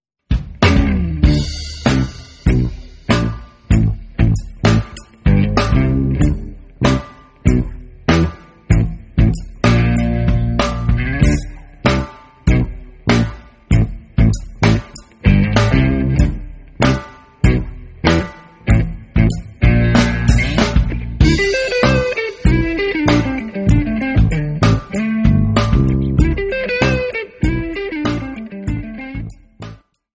easygoing instrumental grooves
which was recorded on the fly in the studio.
Jamband
Jazz
Rock